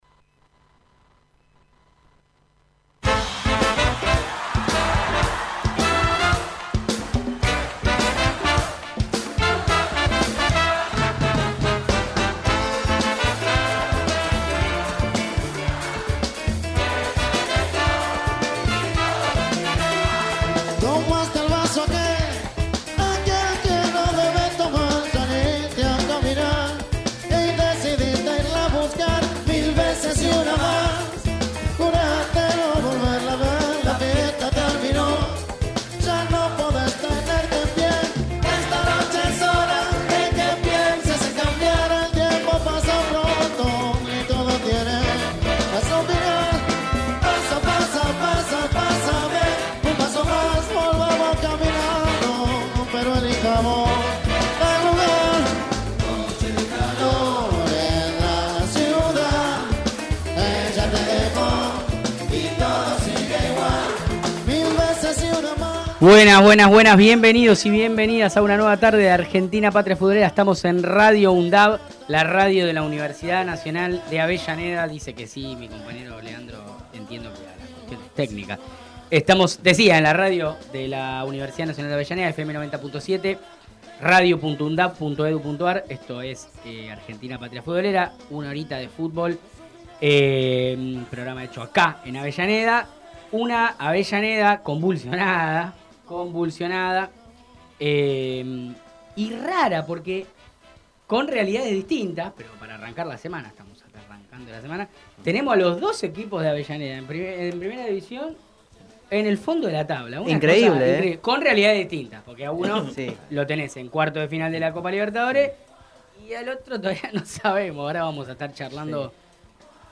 Patria Futbolera Texto de la nota: Un programa sobre fútbol en clave política, social y cultural. Realizado en la radio de la Universidad Nacional de Avellaneda